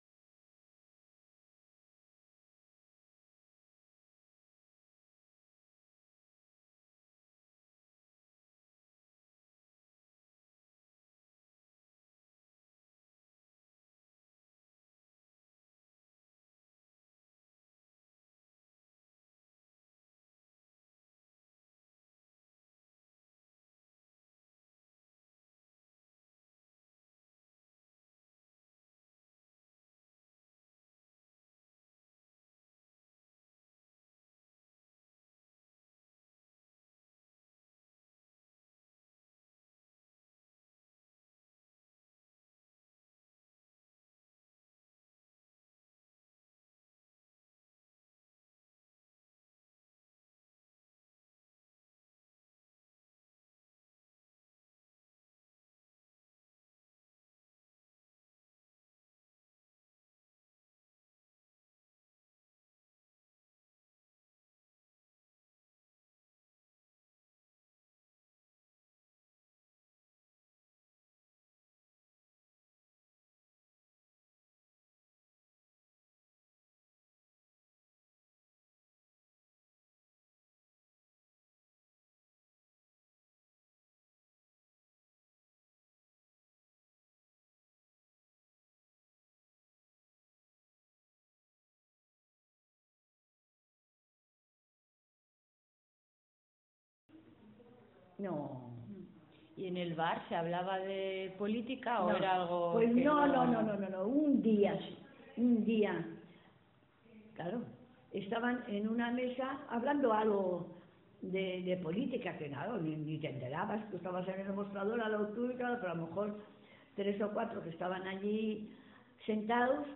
Interviews with women and men who lived the first years of the Franco regime.